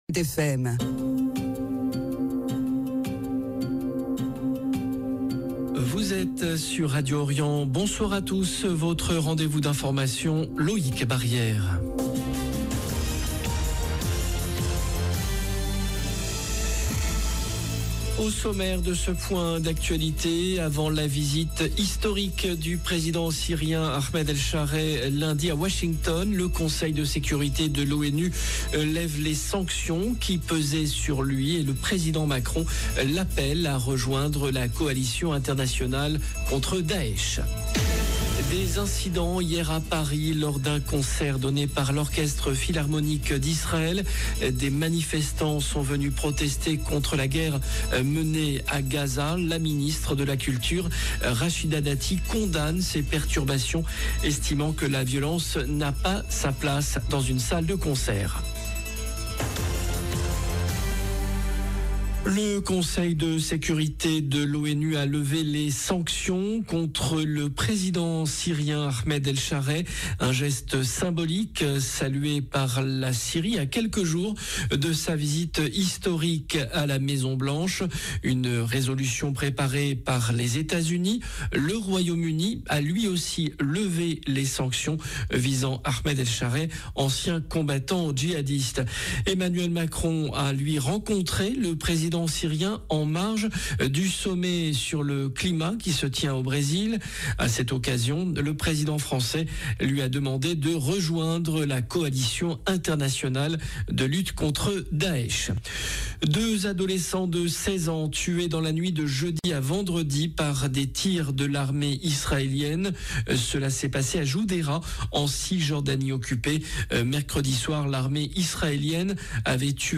JOURNAL DE 17H